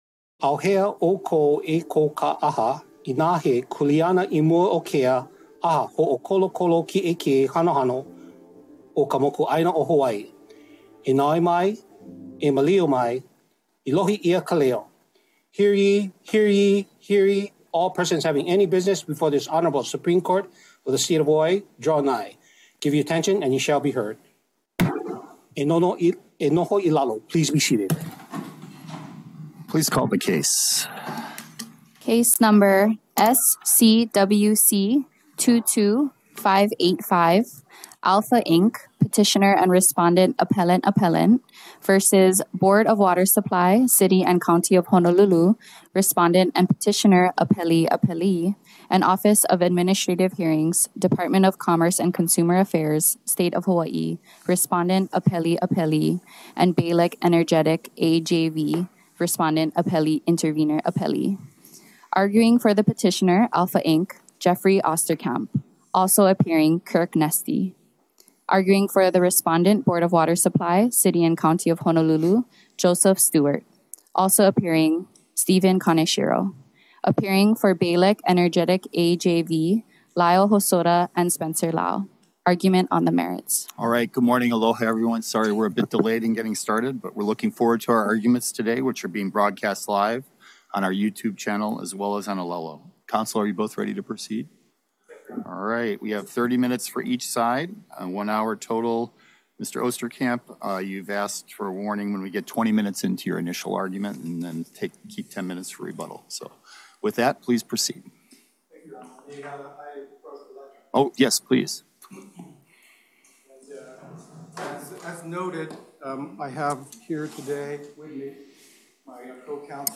The above-captioned case was set for oral argument on the merits at: Supreme Court Courtroom Ali‘iōlani Hale, 2nd Floor 417 South King Street Honolulu, HI 96813